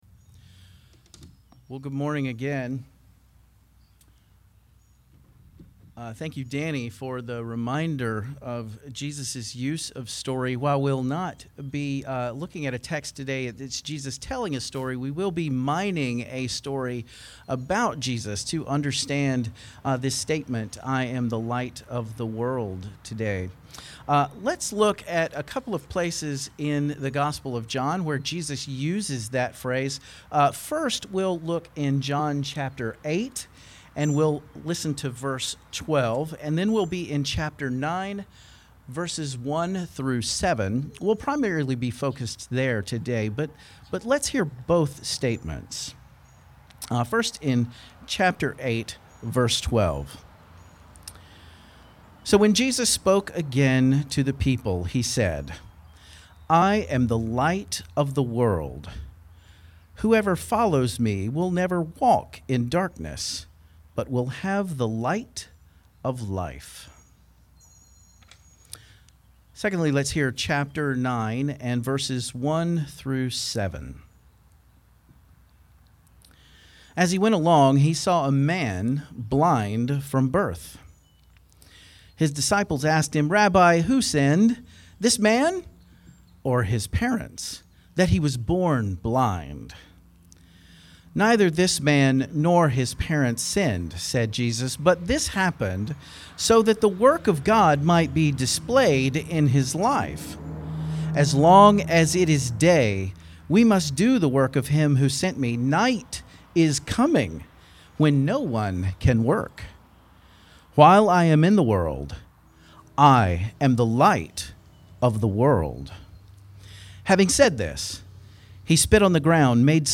Service Type: Guest Preacher